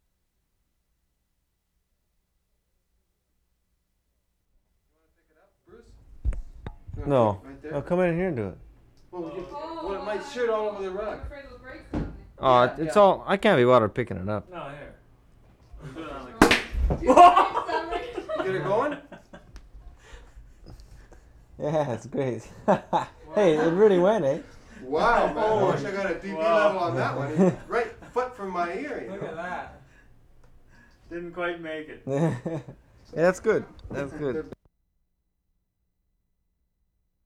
TRENTON, ONTARIO Oct. 31, 1973
CHAMPAGNE BOTTLE OPENED 0'30"
10. Cork pop amidst chatter (0'10"). Not very sensational.